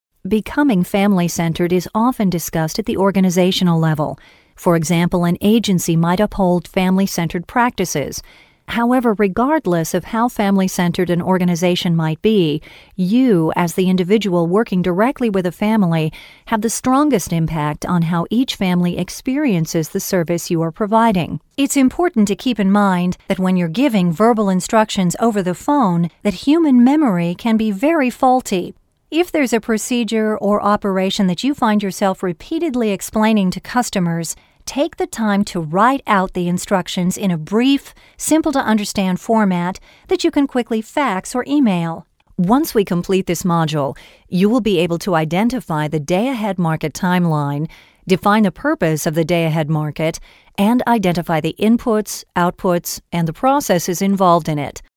With hundreds of clients as a female voice over, she is adept at delivering believable, conversational reads as well as complicated narratives.
englisch (us)
mid-atlantic
Sprechprobe: eLearning (Muttersprache):